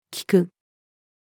chrysanthemum-chrysanthemum-morifolium-female.mp3